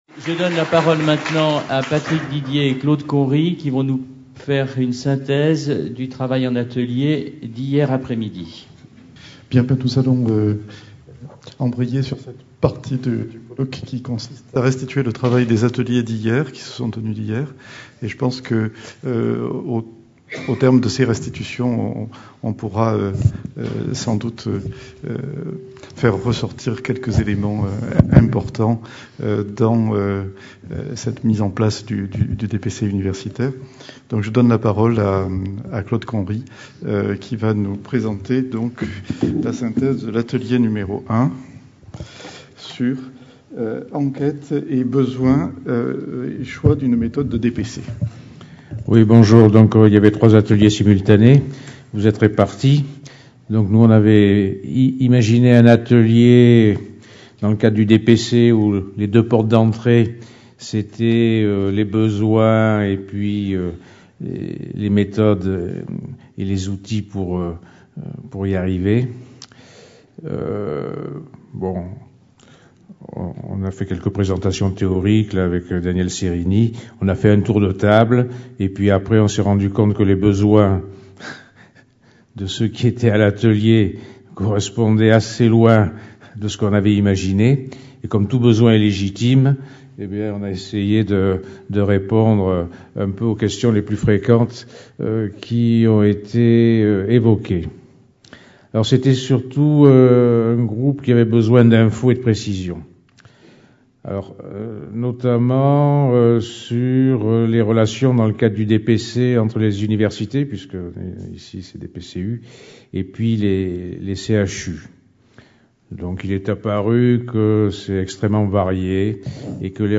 Conférence enregistrée lors du colloque DPC-SU le vendredi 6 juin 2014 à Paris.